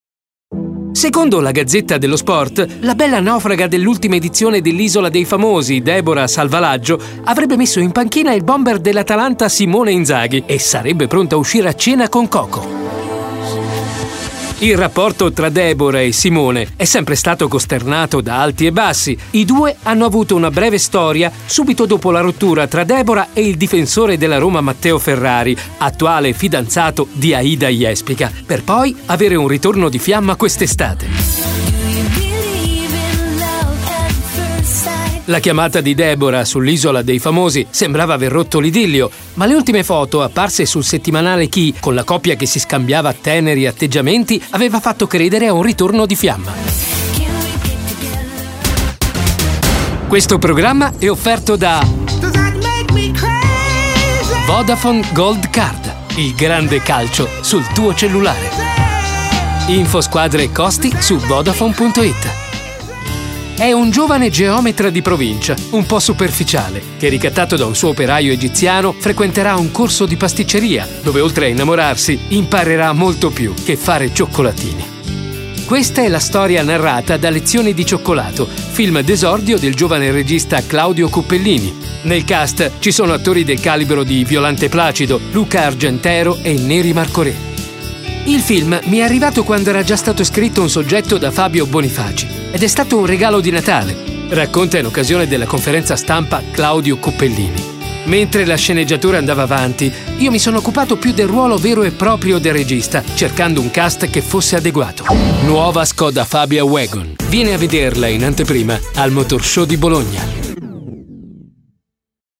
Sprecher italienisch.
Sprechprobe: Sonstiges (Muttersprache):
Italian voice over artist.